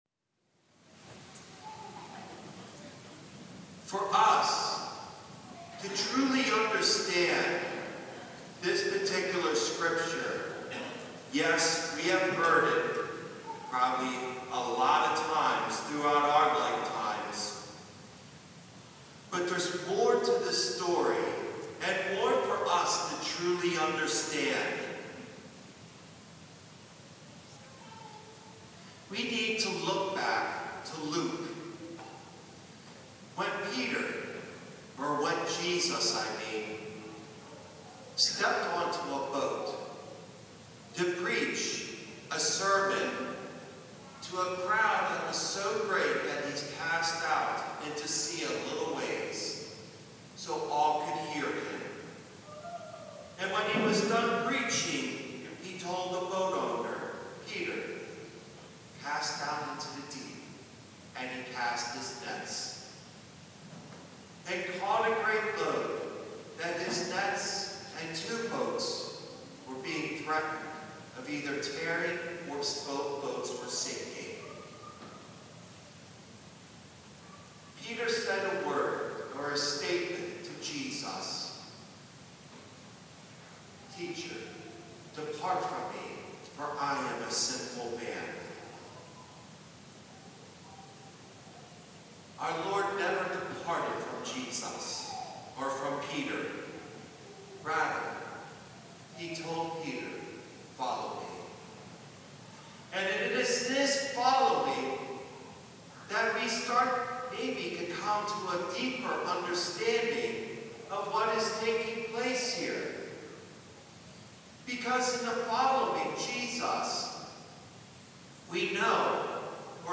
Homily Third Sunday of Easter
homily0504.mp3